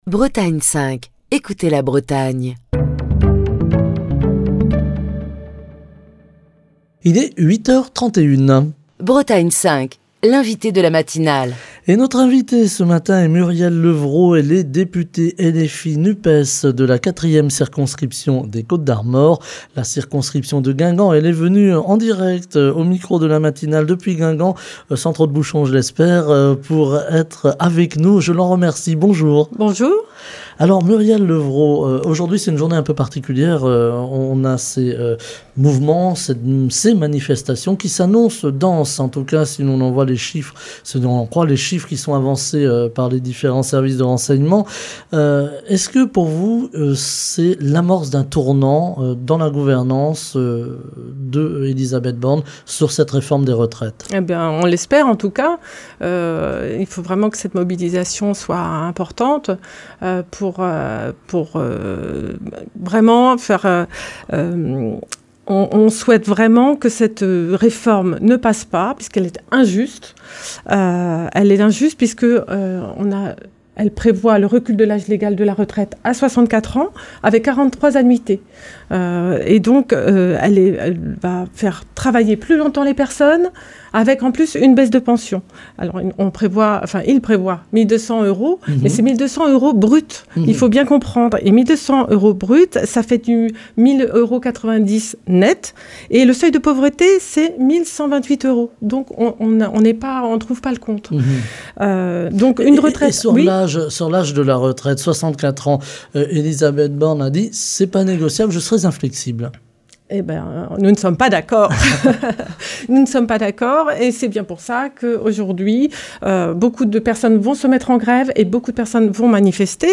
En cette deuxième journée de mobilisation, Murielle Lepvraud, députée LFI-NUPES de la 4ème circonscription des Côtes-d'Armor, invitée de la matinale de Bretagne 5, évoque le projet de réforme des retraites, le contexte social et économique, mais également la disparition de certains services publics comme la fermeture annoncée de la maternité de Guingamp.